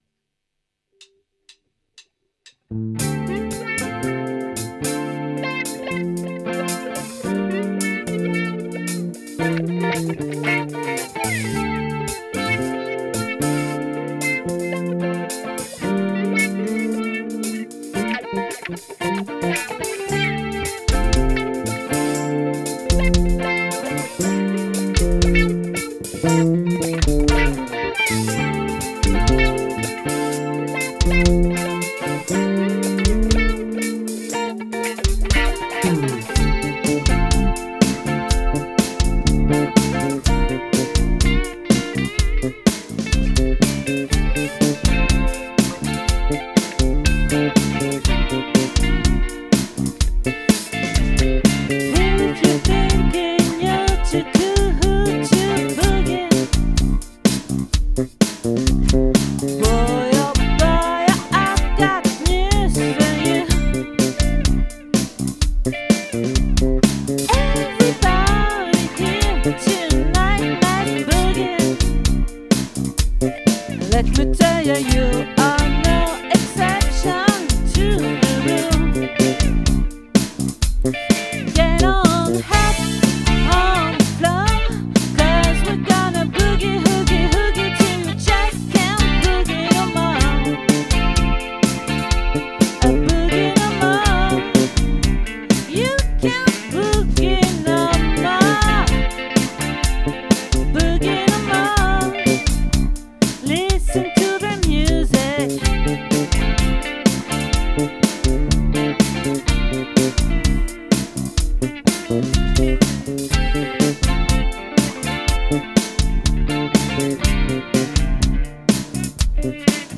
🏠 Accueil Repetitions Records_2022_06_08